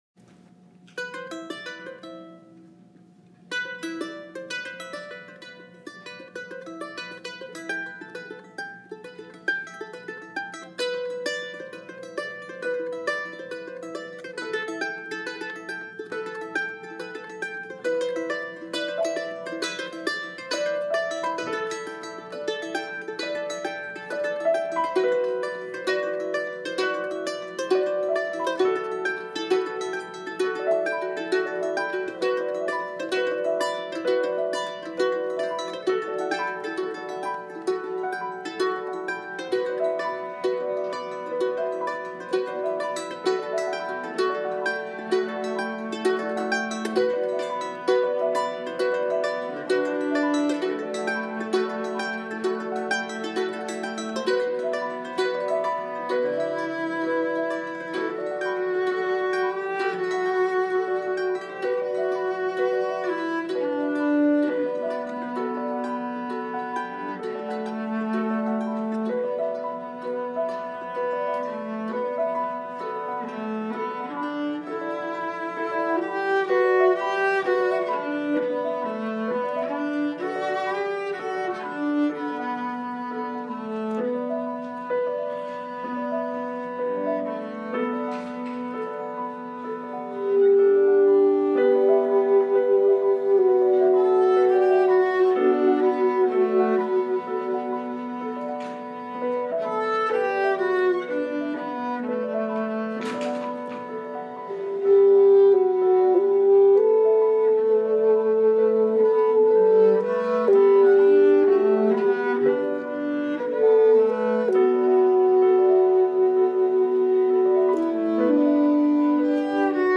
vocals, cello, keyboards, acoustic bass, guitar
guitars, cuatro/charango, Irish bouzouki, vihuela, saxophone